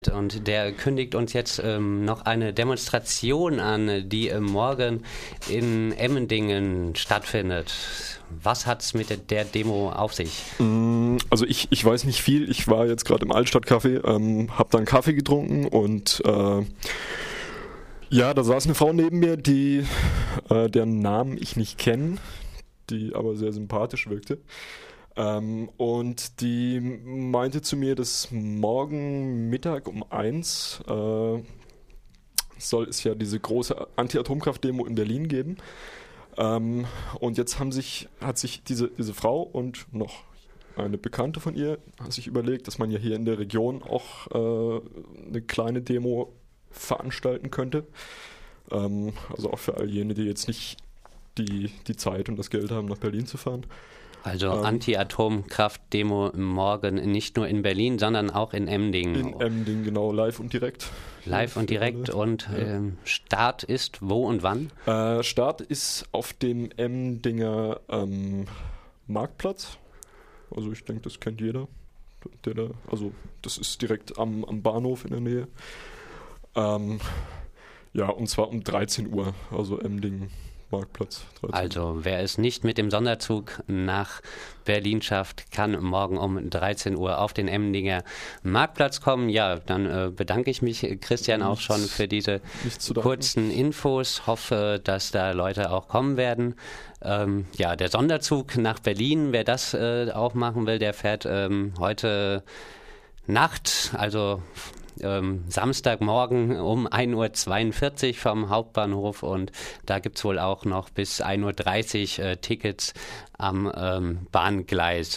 Auch in Freiburg hat ein überparteiliches, eher links orientiertes Bündnis kurzfristig zu einer Kundgebung vor dem Regierungspräsidium aufgerufen zu der rund 600 Menschen kamen. Anschließend formierte sich spontan eine Demonstration, die angeführt von einer Sambaband lautstark durch die Innenstadt zog.
In diesem Beitrag hört ihr nicht die Redebeiträge der Politiker. Wir wollten lieber wissen was die Menschen auf die Straße treibt. Eine rhythmische Collage aus dem heißen Herbst.